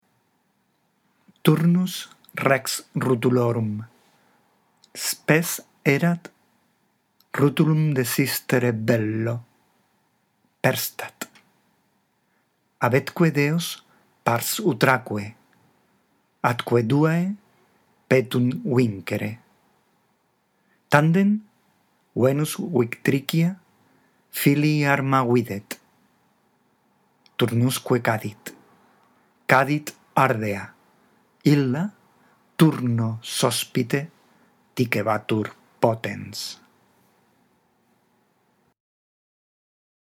La audición de este archivo te ayudará en la práctica de la lectura